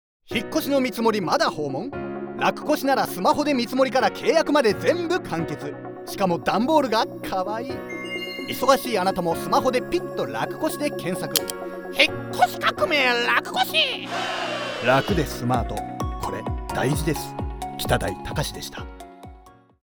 FM局でCM放送中！